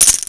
rattle2.wav